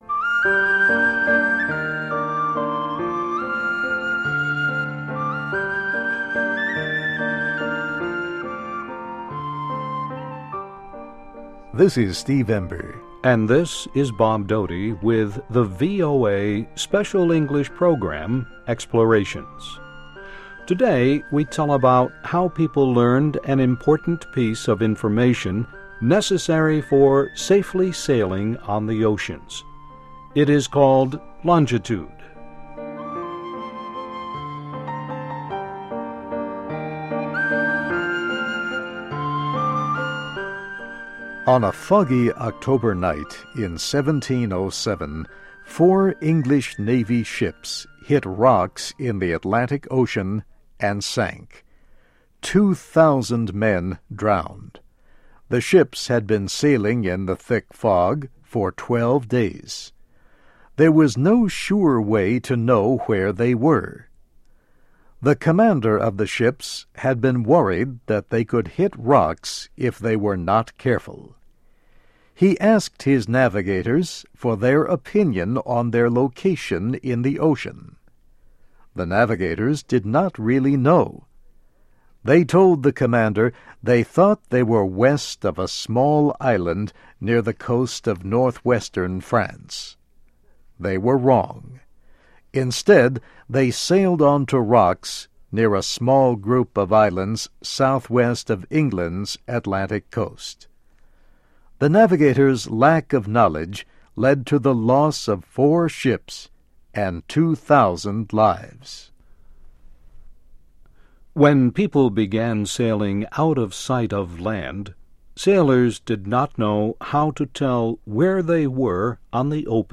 English Listening Practice.